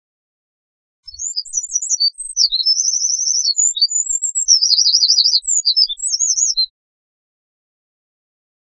〔ミソサザイ〕チョッ，チョッ，チョッ／ジャッ／山地の渓谷など繁った林に棲息，普
misosazai.mp3